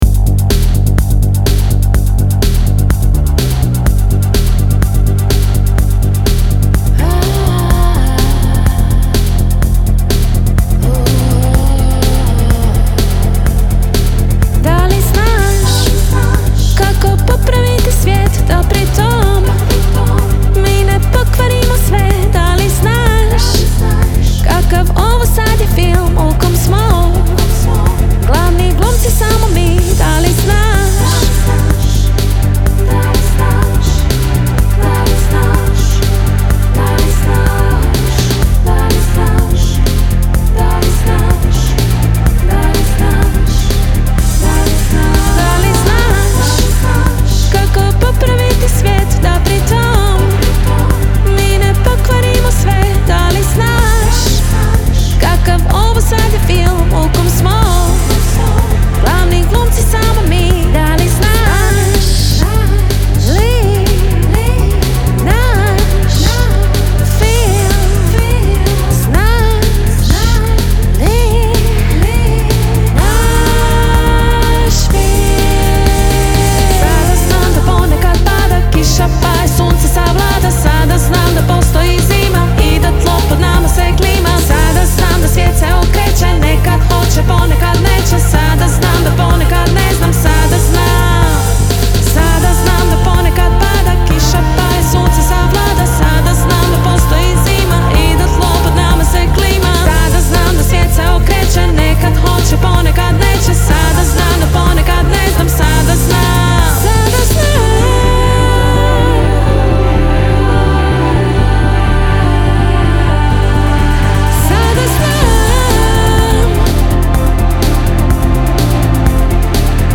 svojim beatom poput brzog vlaka